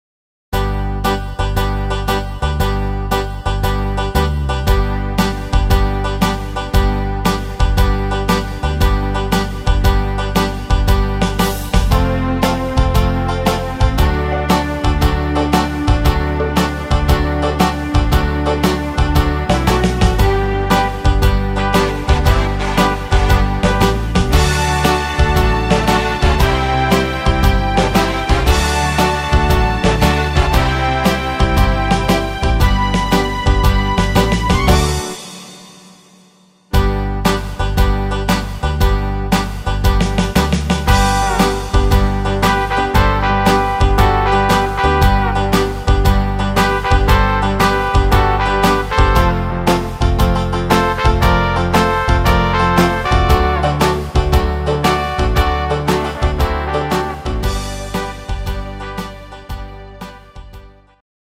Rhythmus  Slowwaltz
Art  Oldies, Englisch